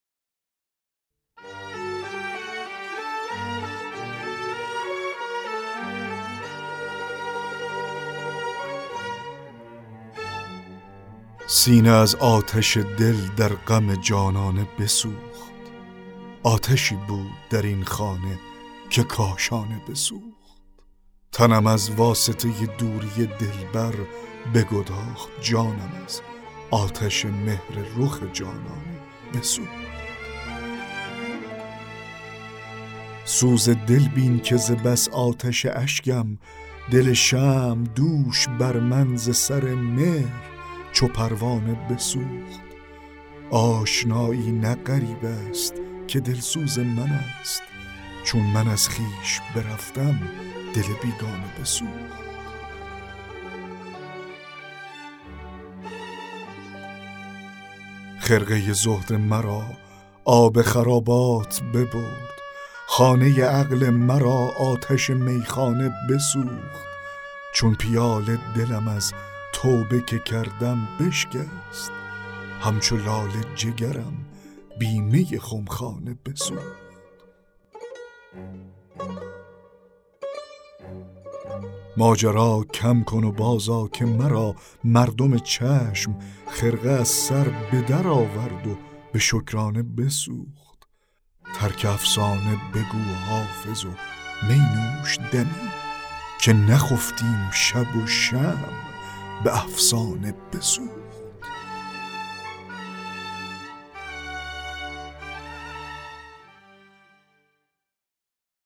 دکلمه غزل 17 حافظ